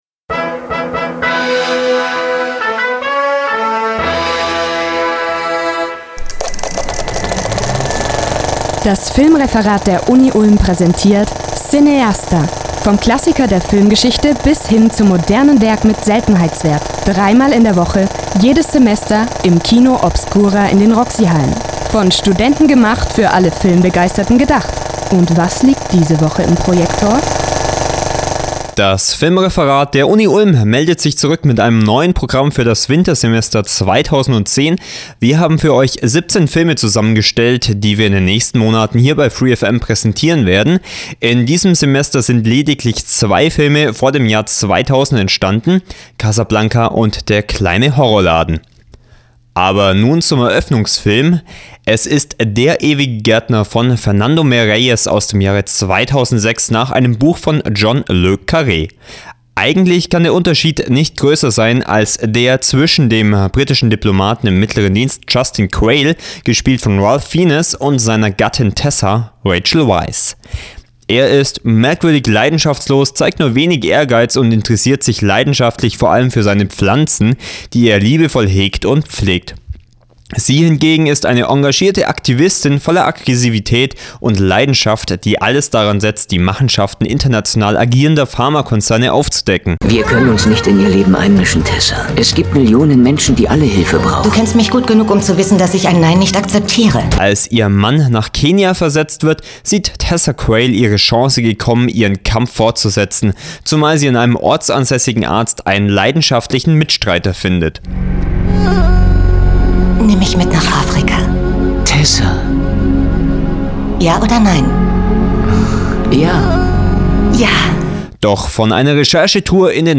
der_ewige_gaertner_ohne_musi.mp3